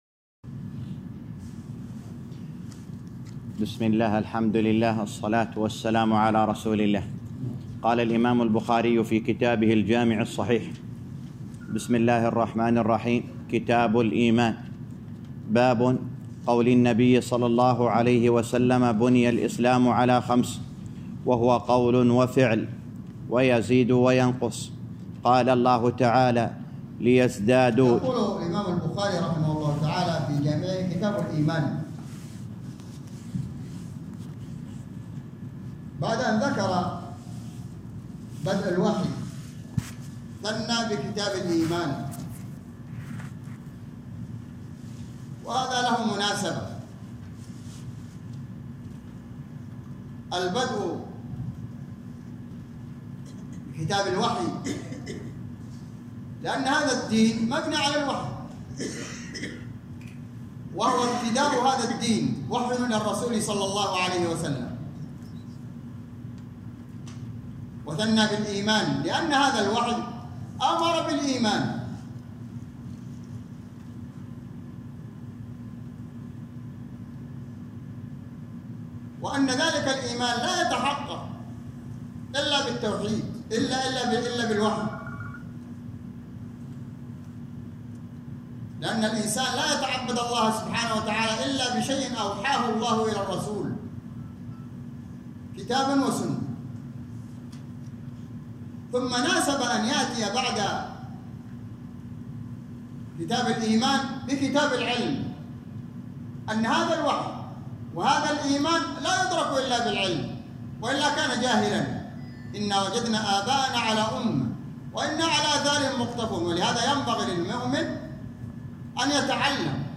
معلومات الملف ينتمي إلى: شرح صحيح البخاري كتاب الإيمان (الشرح الجديد) الدرس الأول- شرح صحيح البخاري كتاب الإيمان _ 1 مشاركة عبر واتس آب مشاركة عبر تيليجرام test An html5-capable browser is required to play this audio.